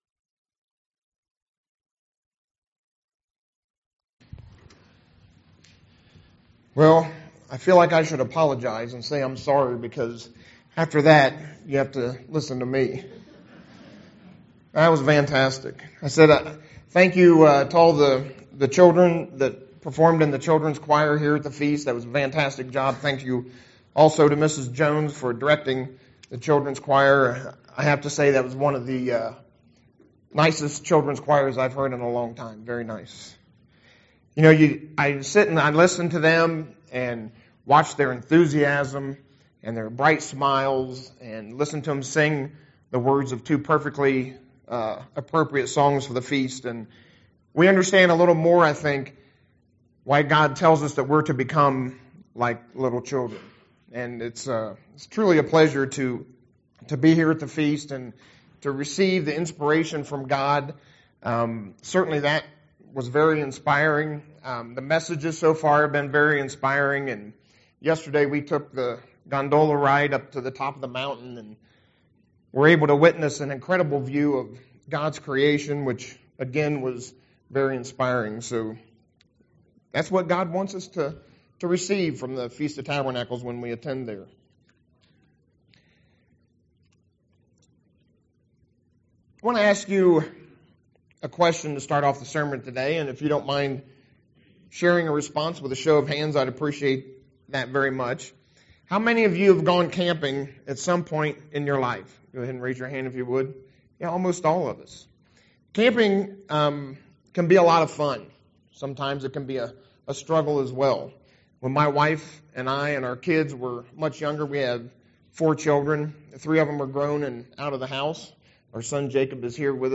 This sermon was given at the Steamboat Springs, Colorado 2017 Feast site.